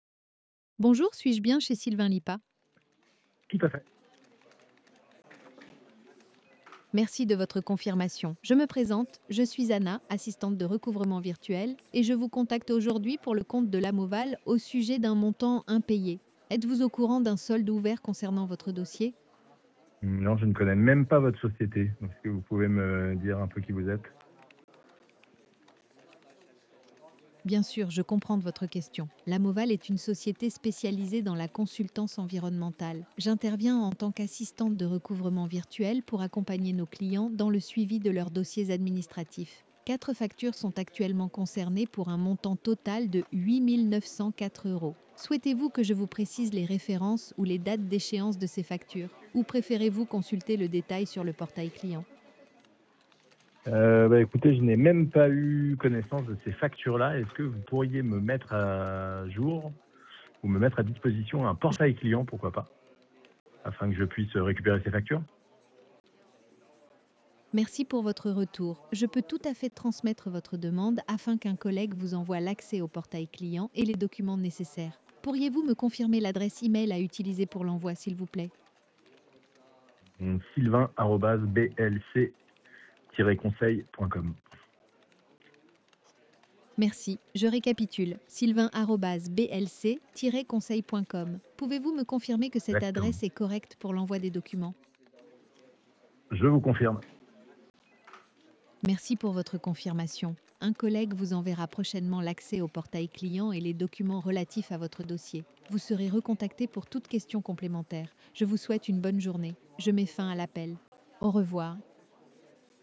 Découvrez ci-dessous 3 enregistrements d’appels IA que nous avons testés.